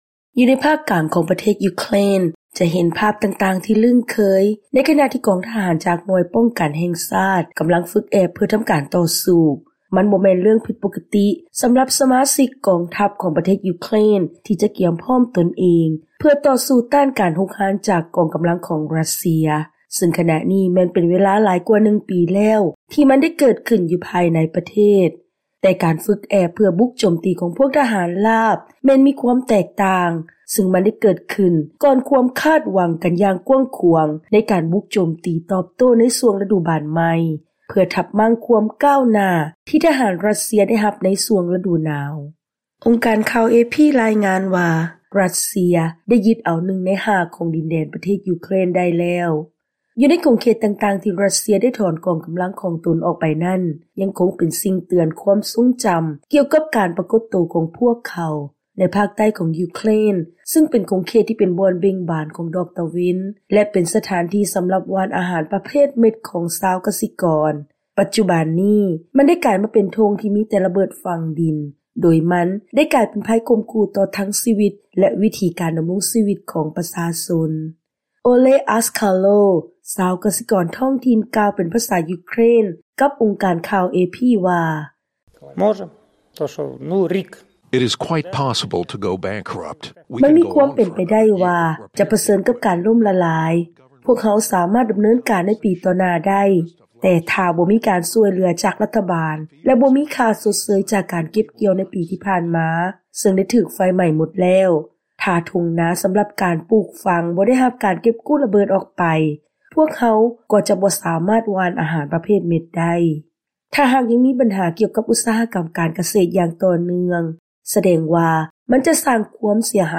by ສຽງອາເມຣິກາ ວີໂອເອລາວ